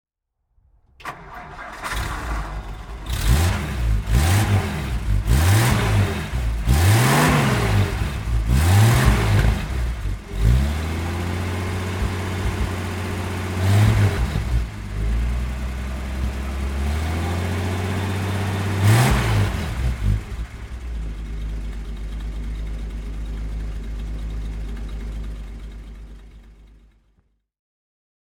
Nash Rambler Custom (1954) - Starten und Leerlauf